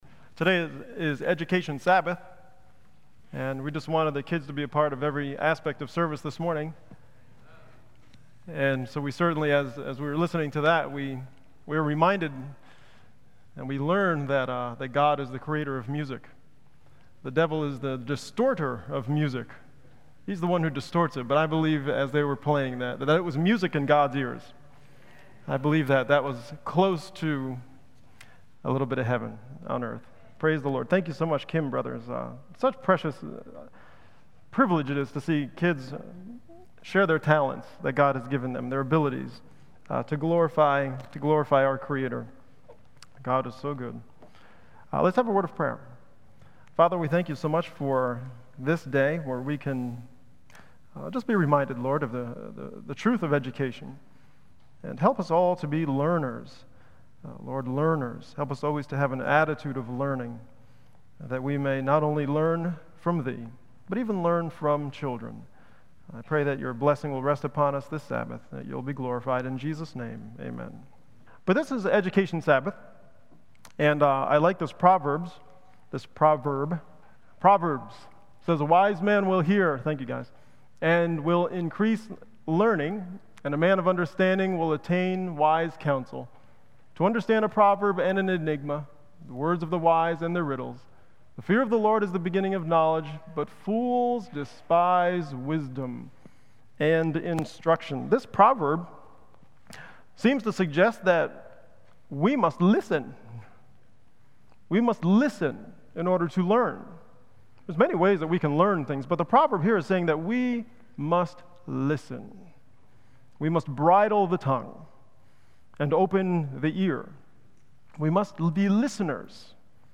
Sabbath Sermons Download Other files in this entry